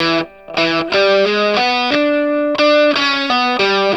WALK1 60 E+.wav